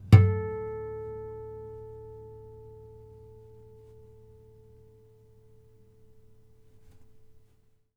harmonic-06.wav